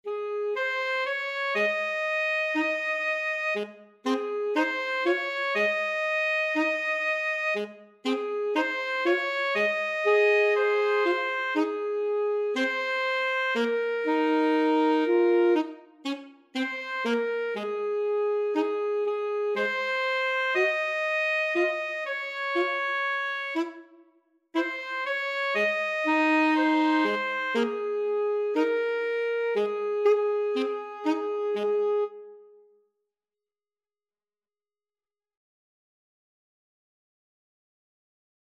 American gospel hymn.
4/4 (View more 4/4 Music)
Vivo
Arrangement for Alto Saxophone Duet
Db major (Sounding Pitch) Ab major (French Horn in F) (View more Db major Music for Alto Saxophone Duet )